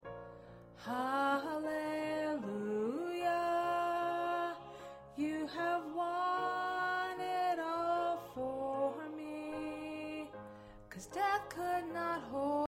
Lead part.